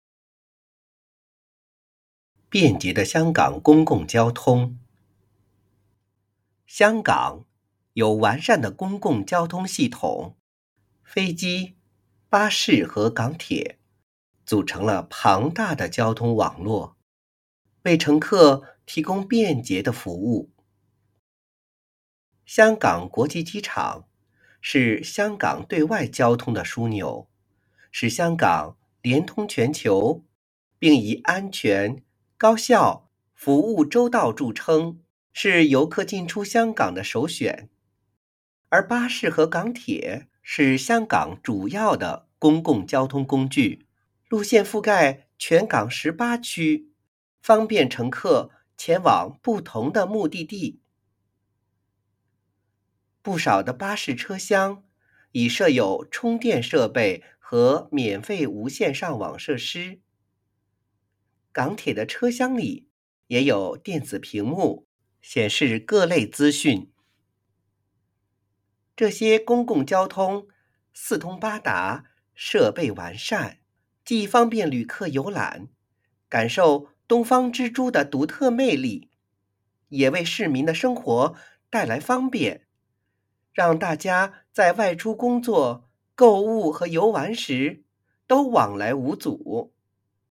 編號篇名篇章及學與教建議朗讀示範篇章附拼音